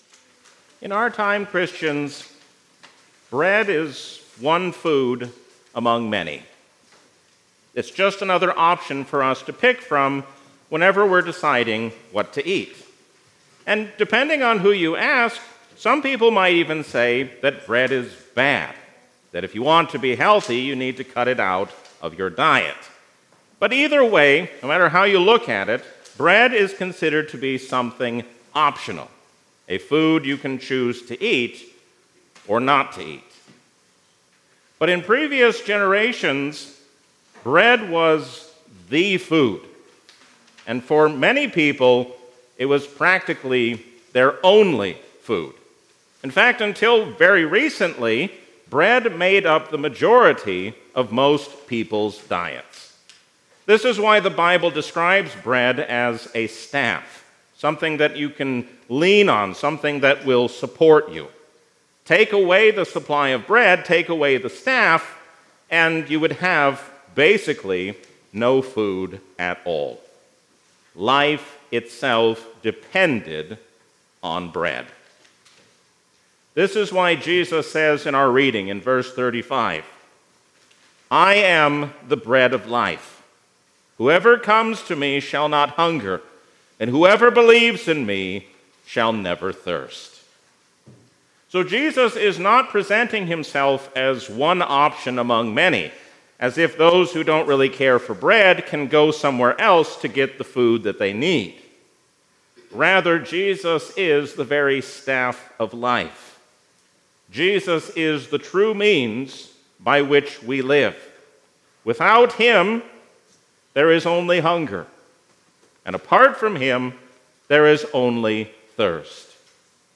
A sermon from the season "Epiphany 2026." Always be on guard against error hiding itself behind the truth.